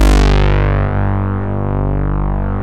MOOG #5  G2.wav